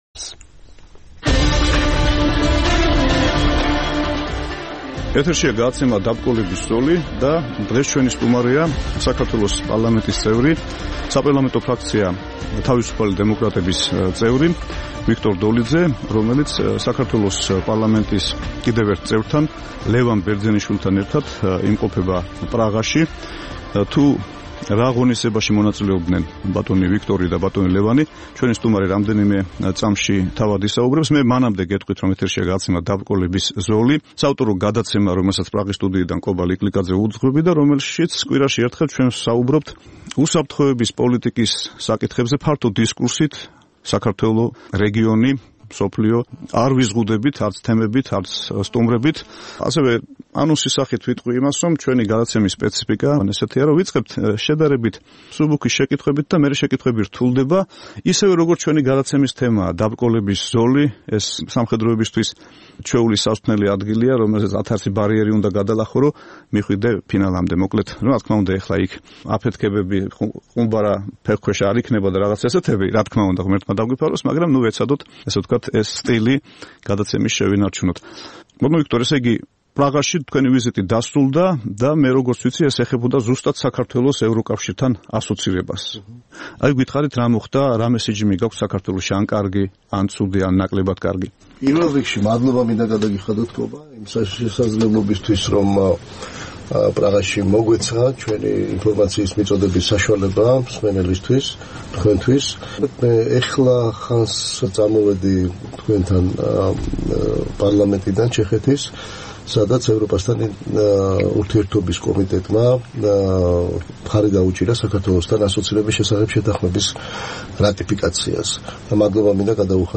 საუბარი ვიქტორ დოლიძესთან